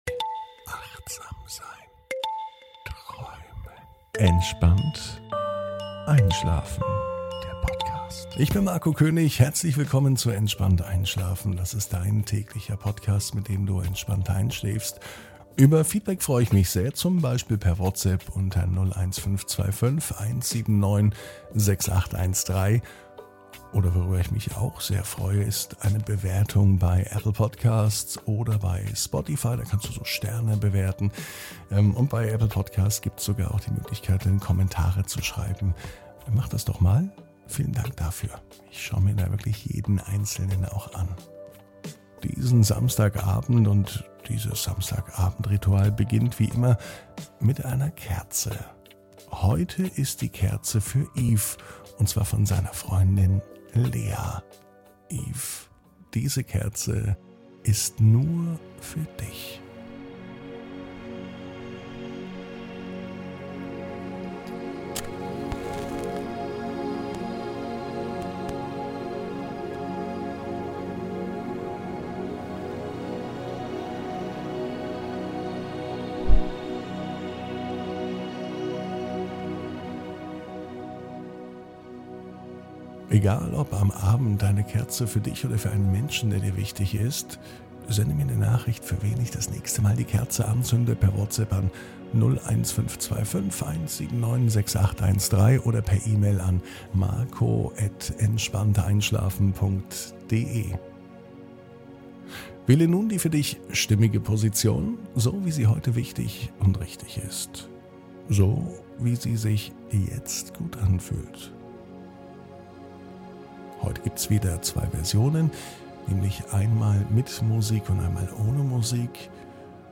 (ohne Musik) Entspannt einschlafen am Samstag, 13.08.22 ~ Entspannt einschlafen - Meditation & Achtsamkeit für die Nacht Podcast